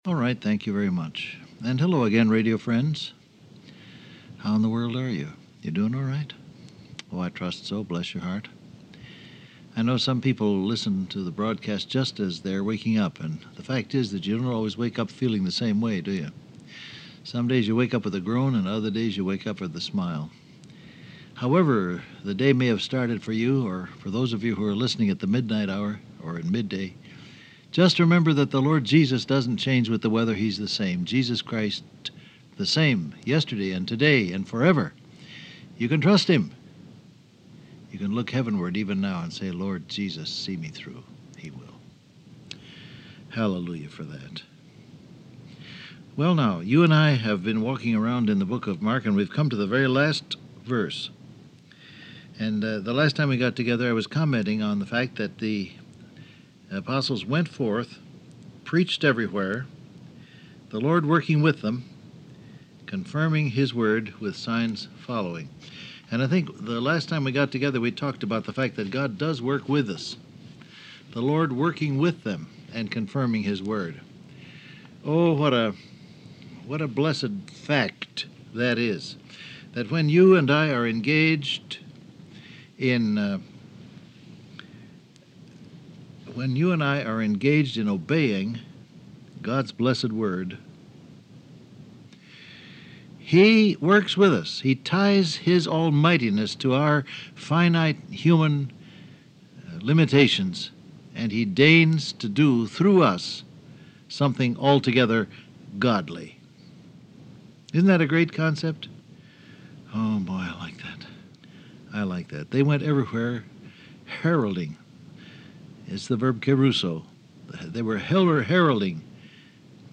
Download Audio Print Broadcast #6629 Scripture: Mark 16:19 Topics: Men , Trials , Miracles , Suffering , Good , Elijah , Crisis , Fishers Transcript Facebook Twitter WhatsApp Alright, thank you very much.